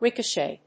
発音記号
• / ríkəʃèɪ(米国英語)
• / ˈɹɪkəʃeɪ(英国英語)